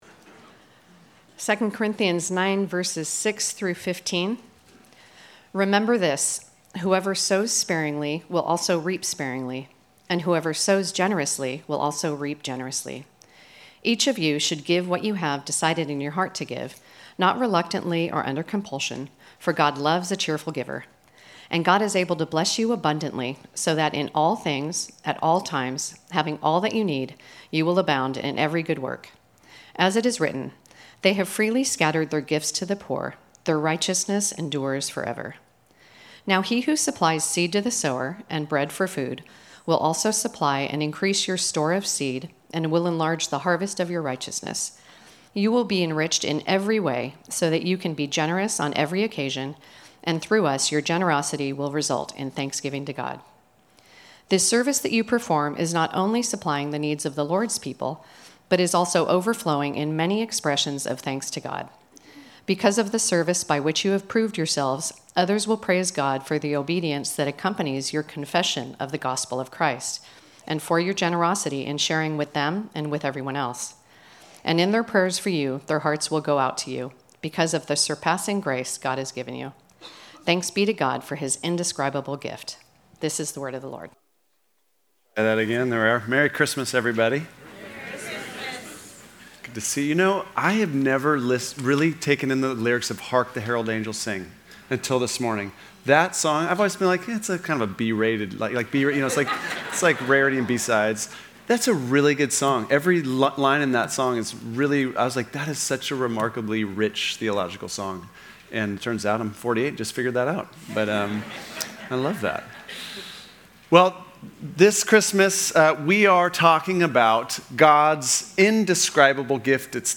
Sermons from Grace Fellowship Church